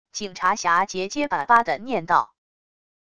警察侠结结巴巴地念道wav音频